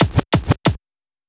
FK092BEAT5-R.wav